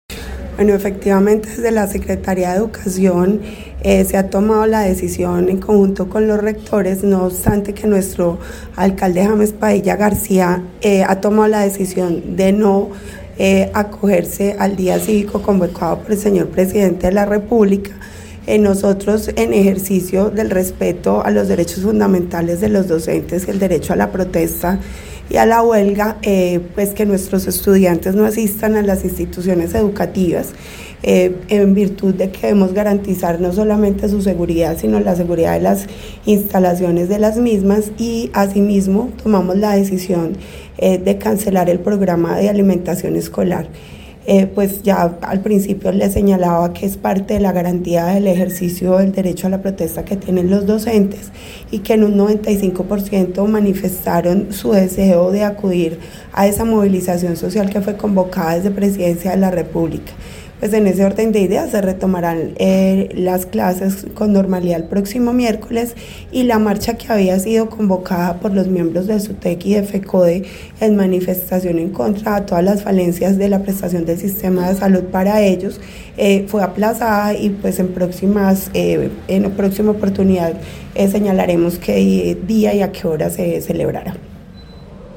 Paula Huertas secretaria de educación de Armenia